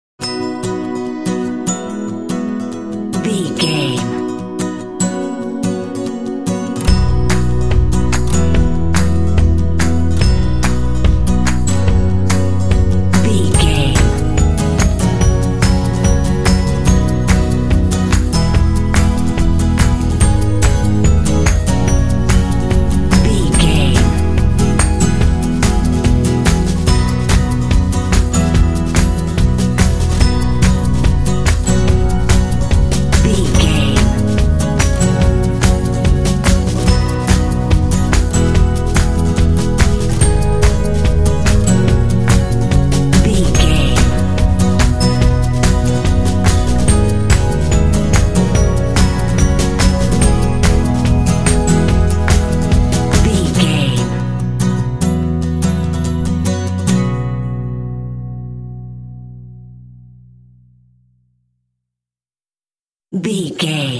Ionian/Major
Fast
happy
energetic
acoustic guitar
bass guitar
drums
percussion
alternative rock
pop
indie